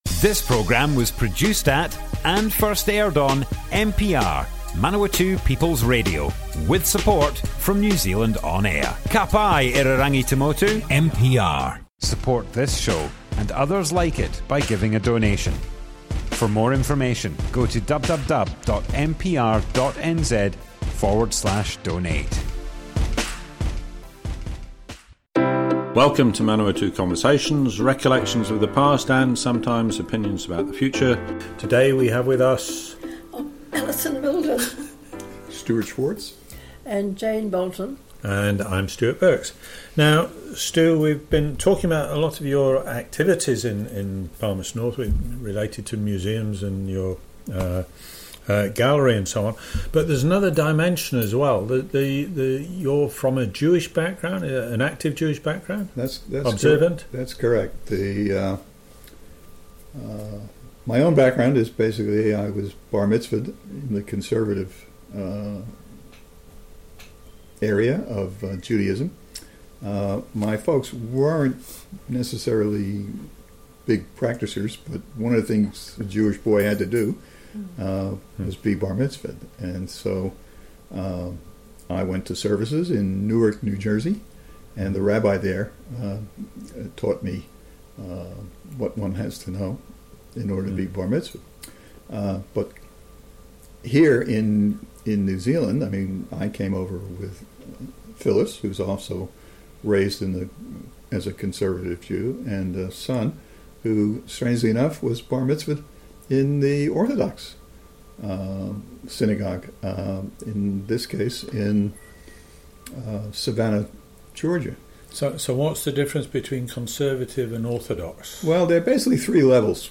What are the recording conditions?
Manawatu Conversations More Info → Description Broadcast on Manawatu People's Radio, 1st Septermber 2020.